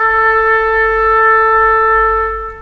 A4_oboe.wav